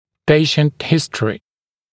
[‘peɪʃnt ‘hɪstrɪ][‘пэйшнт ‘хистри]история болезни, анамнез